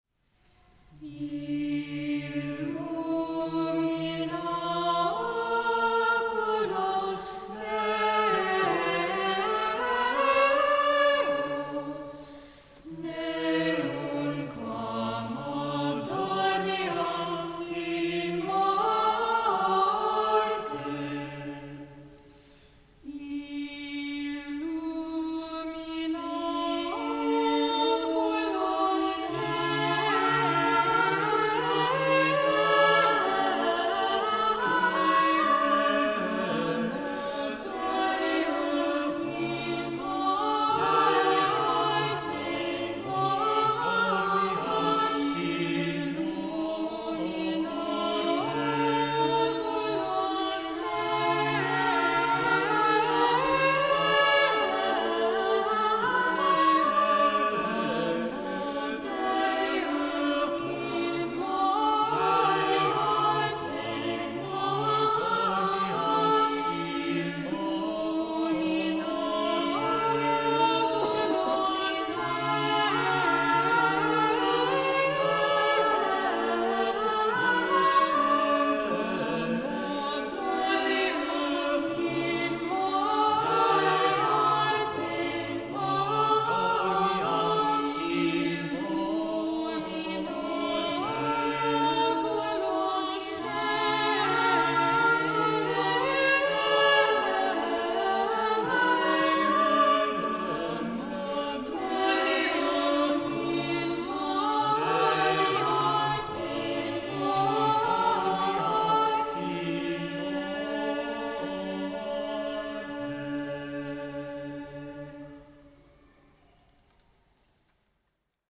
Illumina Oculos Meos (Round)
This simple round is a gem! Drawn from Psalm 12, this penitential motet features a plaintive descending line that reflects the sorrow of the text.
Live recording • Illumina Oculos Meos